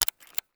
CAMERA_Shutter_03_mono.wav